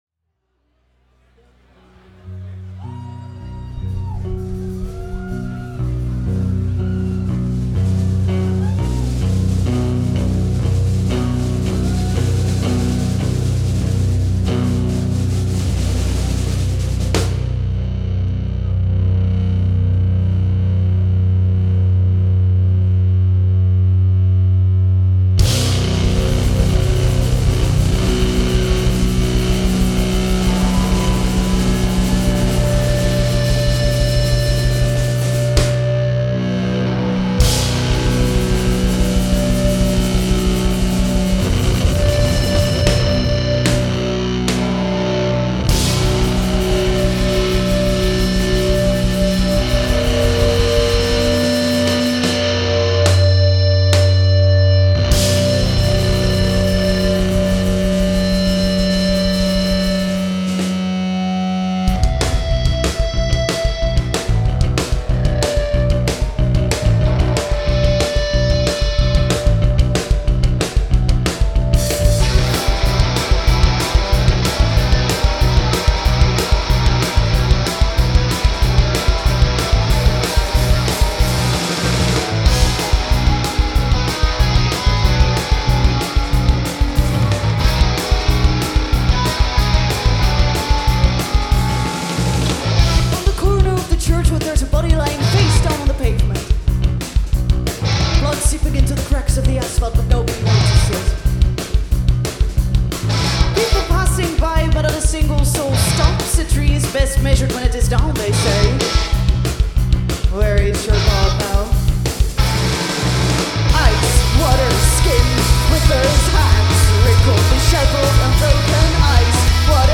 noise-makers